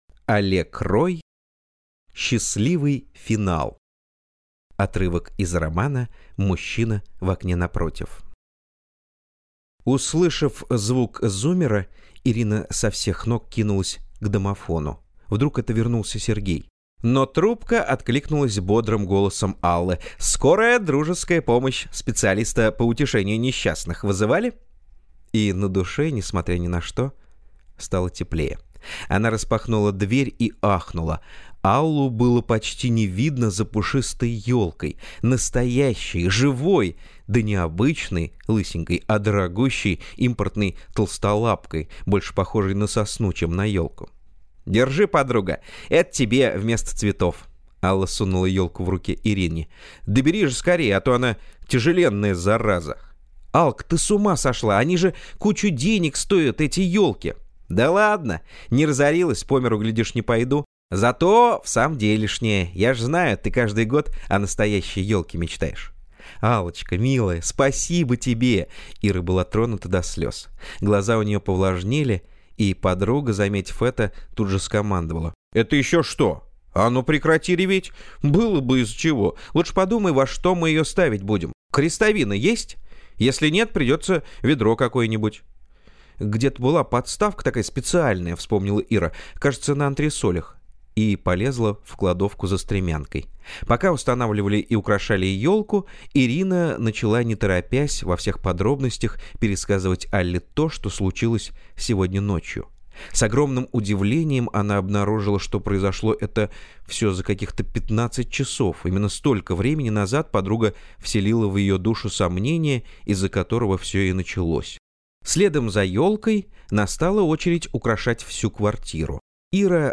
Аудиокнига Счастливый финал | Библиотека аудиокниг
Прослушать и бесплатно скачать фрагмент аудиокниги